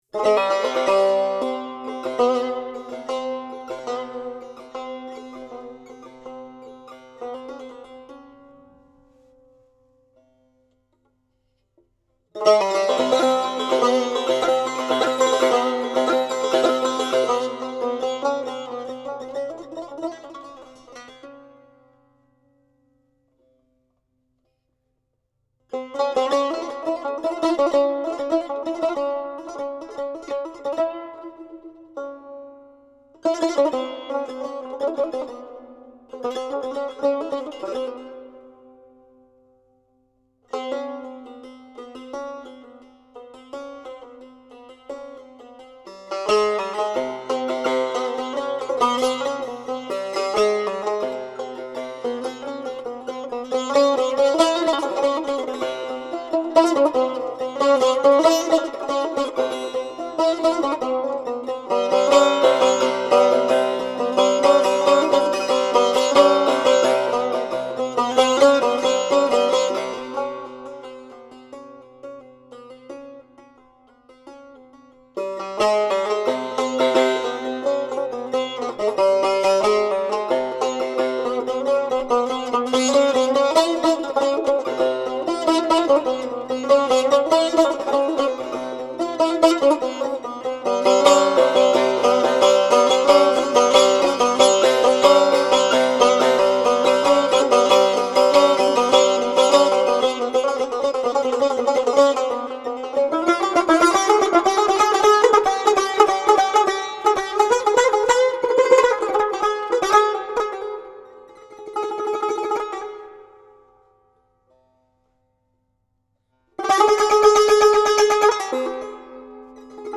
دسته : سنتی ایرانی